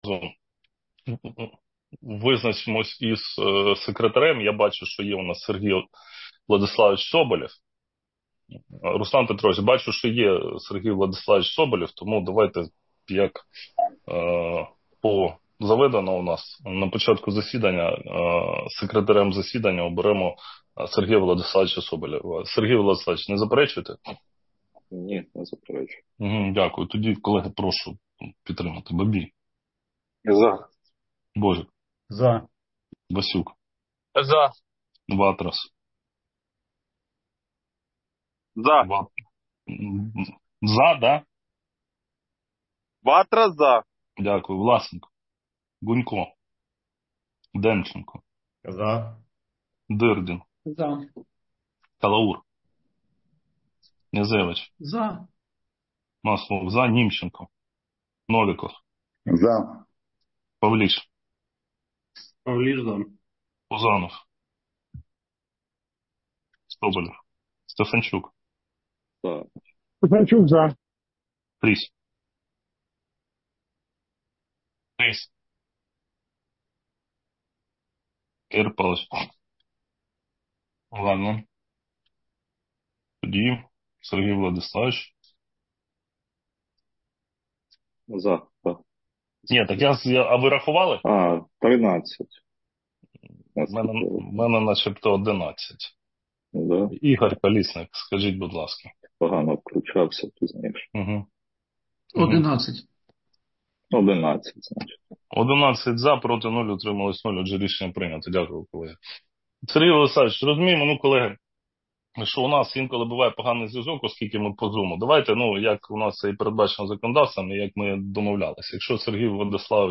Аудіозаписи засідань Комітету за грудень 2024 року
Назва файлу - Аудіозапис засідання від 12 грудня 2024 року